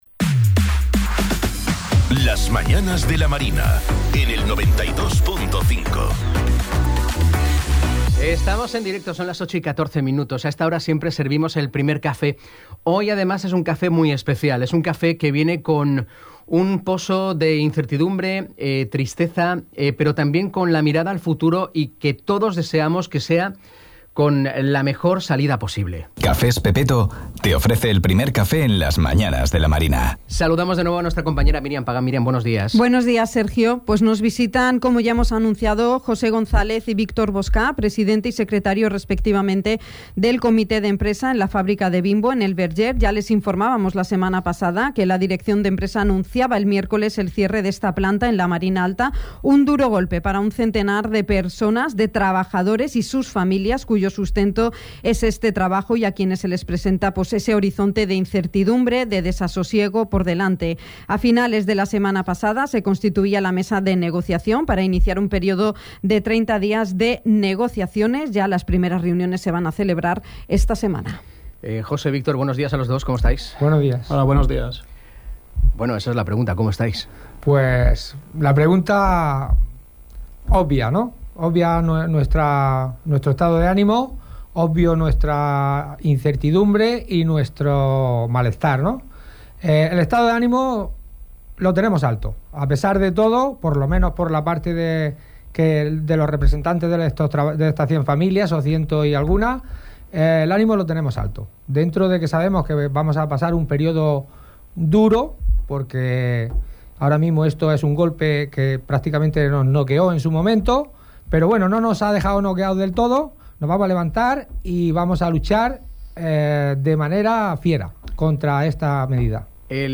Entrevista-Comite-Empresa-Bimbo.mp3